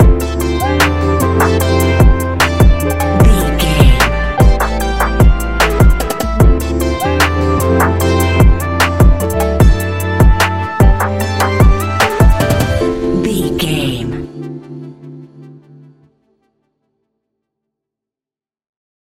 Ionian/Major
laid back
Lounge
sparse
new age
chilled electronica
ambient
atmospheric
morphing
instrumentals